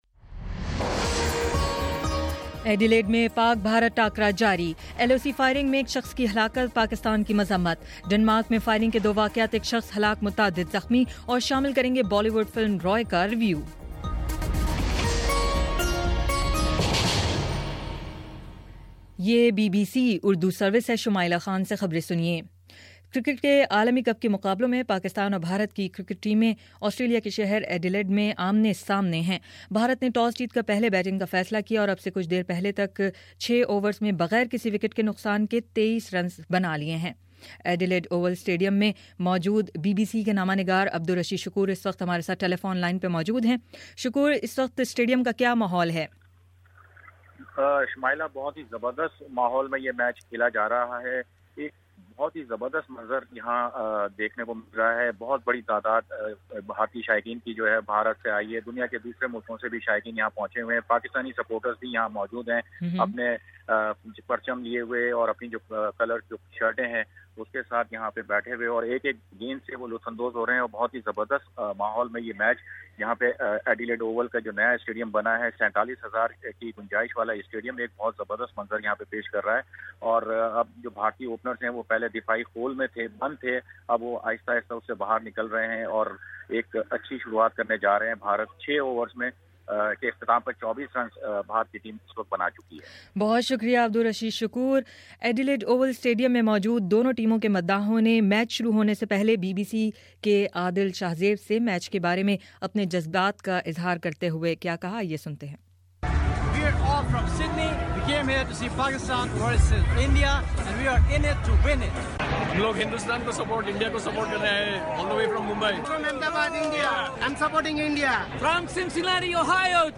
فروری 15 : صبح نو بجے کا نیوز بُلیٹن